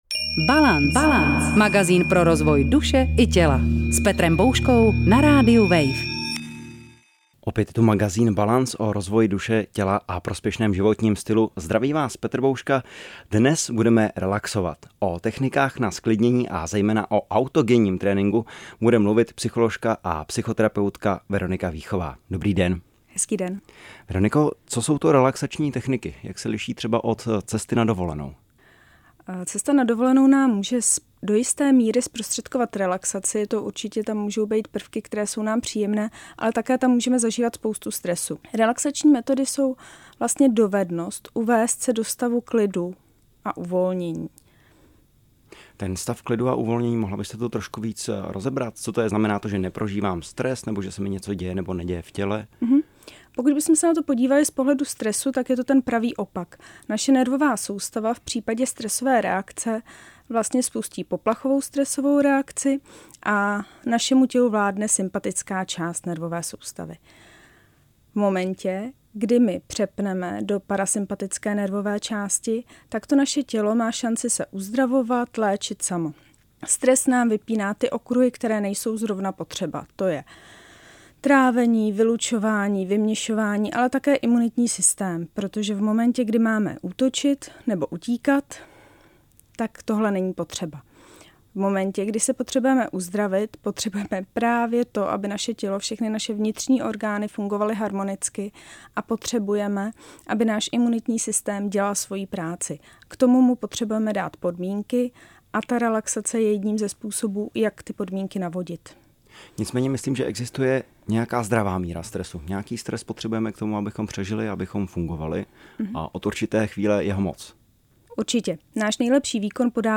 Hovoria lektori aj absolventi - letci, pedagógovia, moderátori, manažéri, lekári, športovci, ... .Vyjadrenia o prínose autogénneho tréningu.